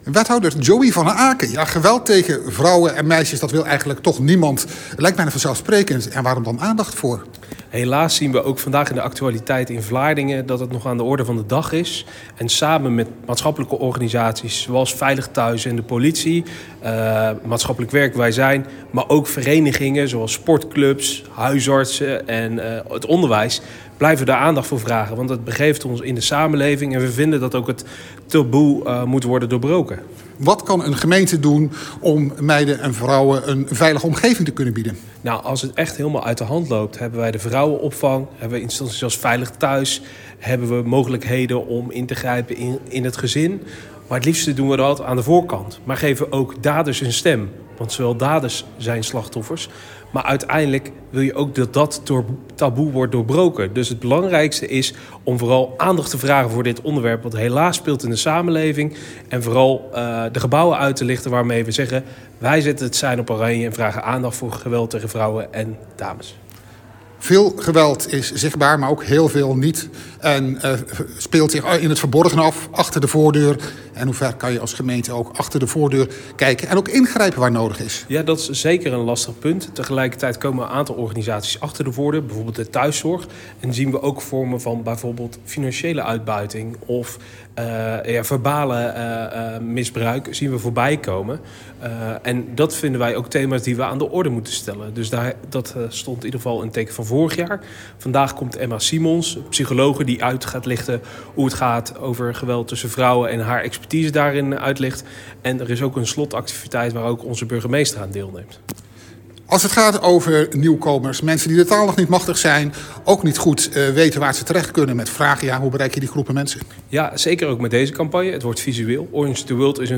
Audio: Wethouder Joey van Aken, over het belang van acties als Orange the World.